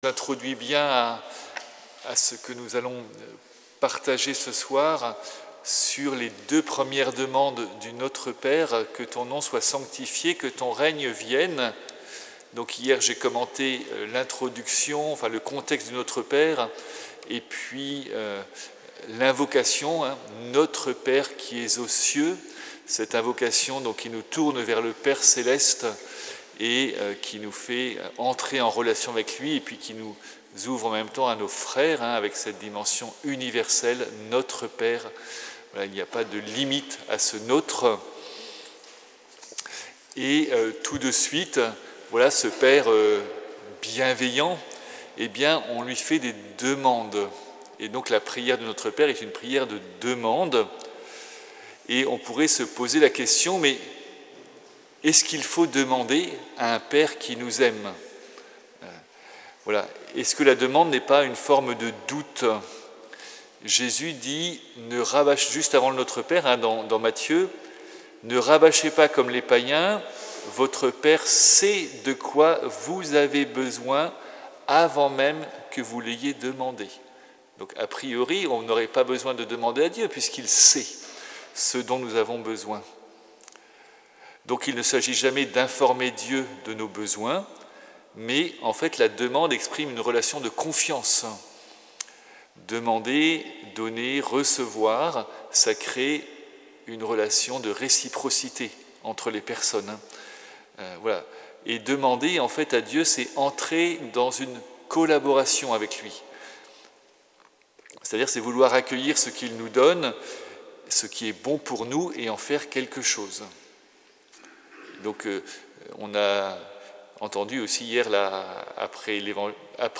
Audio : Notre Père pour la retraite paroissiale du 23 mars 2026.